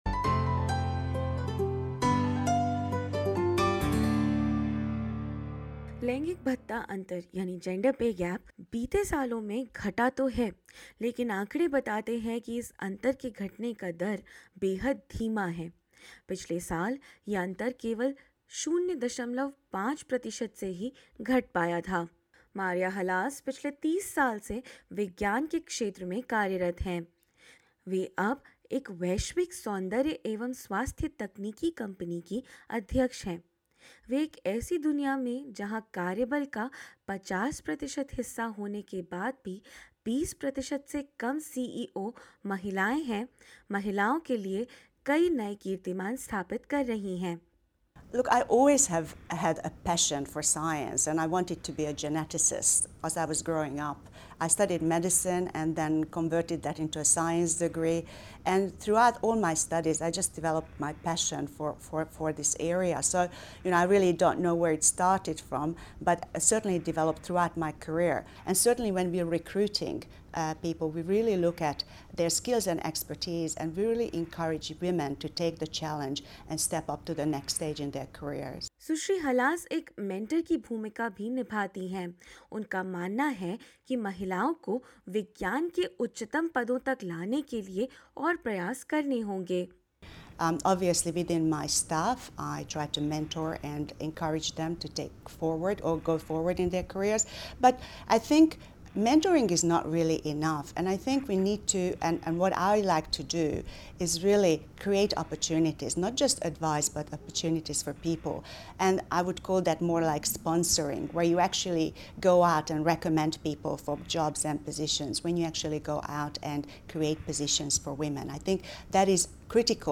क्या हैं यह पद्यतियां, और क्यों वेतन की यह असामनता नहीं हो रही है कम, जानेंगे इस रिपोर्ट में।